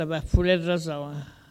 collecte de locutions vernaculaires
Catégorie Locution